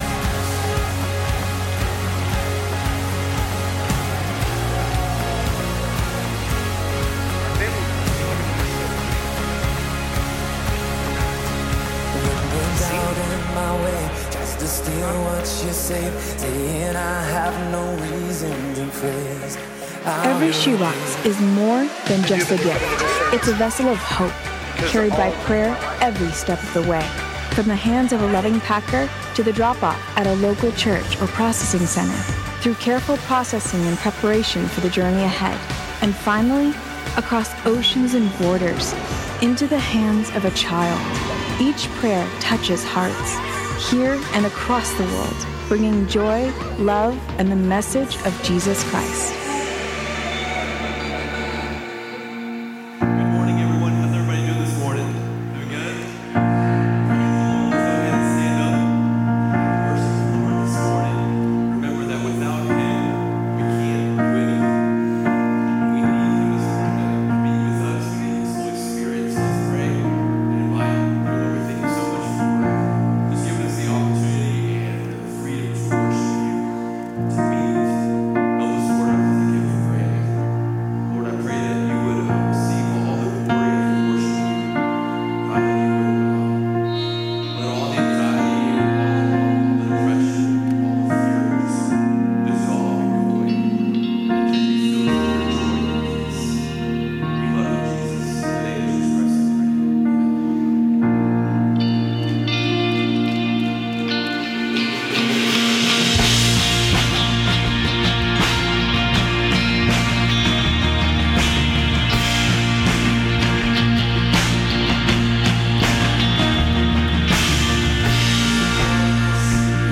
Calvary Knoxville Sunday AM Live!